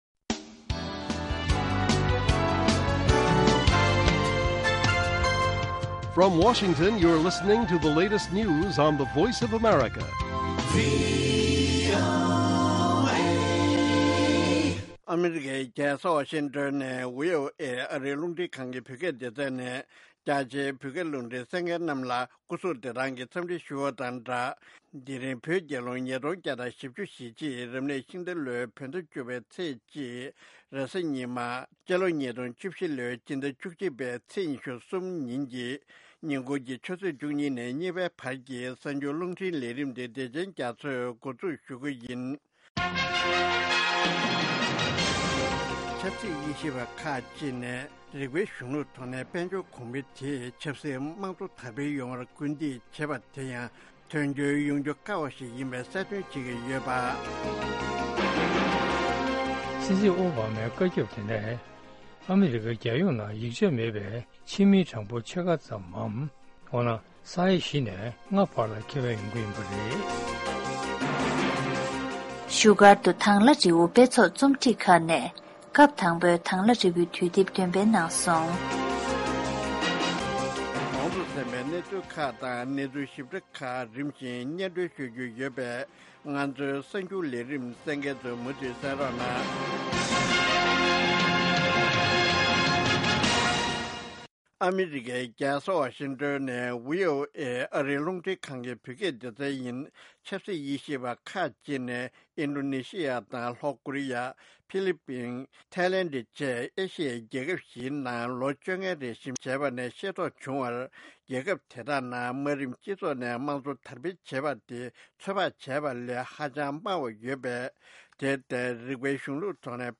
ཉིན་གུང་གི་གསར་འགྱུར།